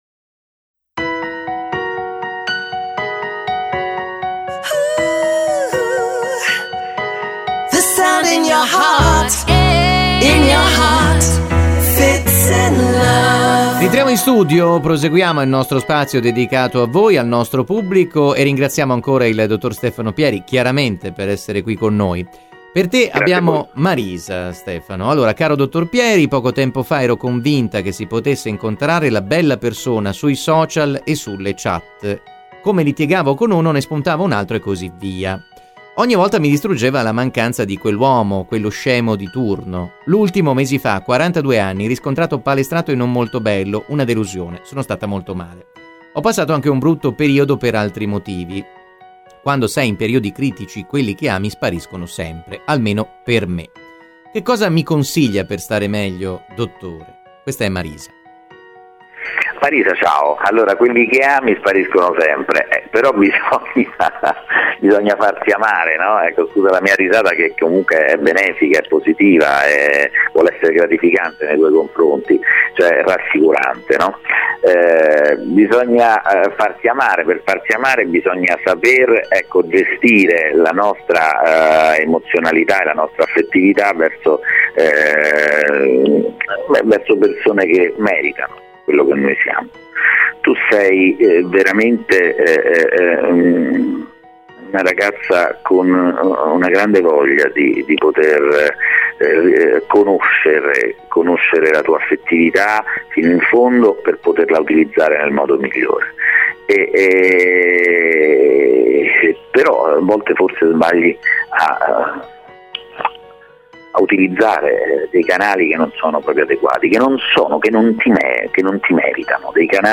psicologo e psicoterapeuta.